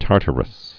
(tärtər-əs)